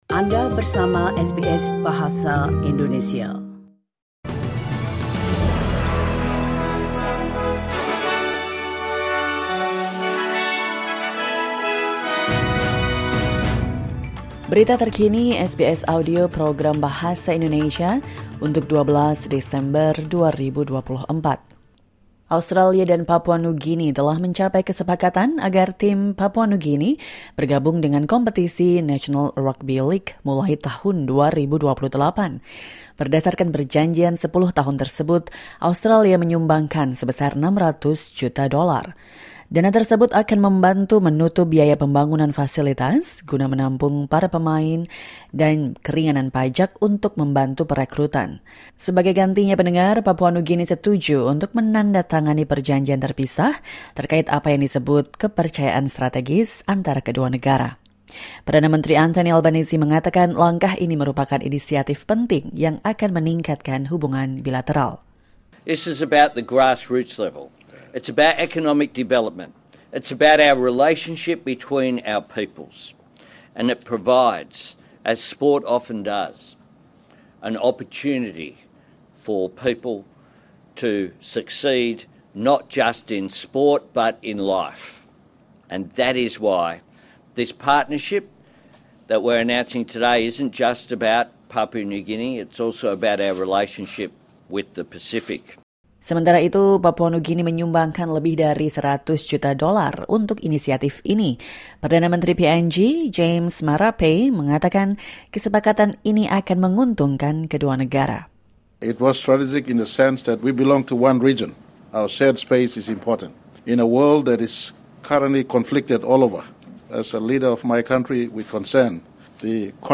Latest News SBS Audio Indonesian Program - 12 December 2024